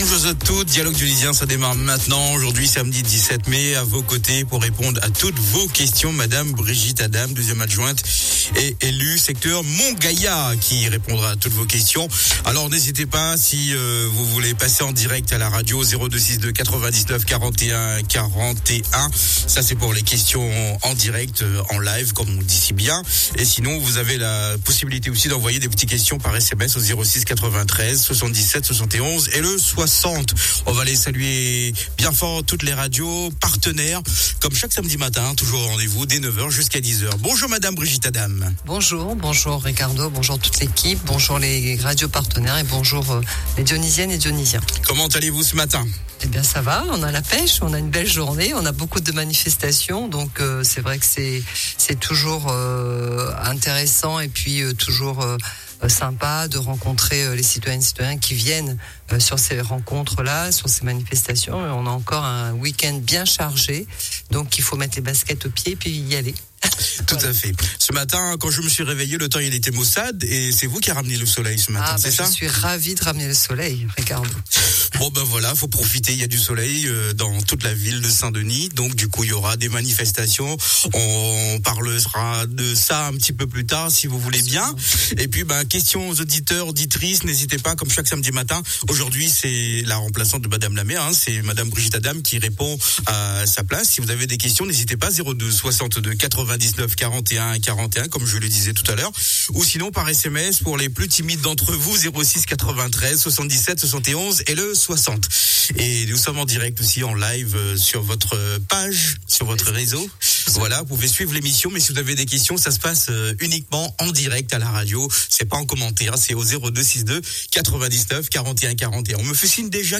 La maire de Saint-Denis, Mme Éricka Bareigts répond aux questions des auditeurs et revient sur les questions posées la semaine passée.